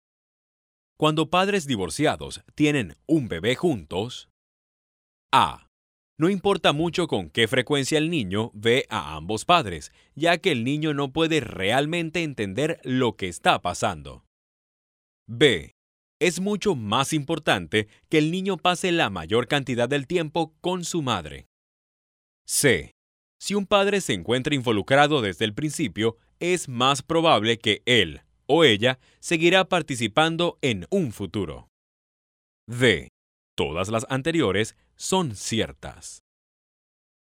I have Spanish Neutral accent. I have my own studio with professional equipments, for record my proyects.
Sprechprobe: eLearning (Muttersprache):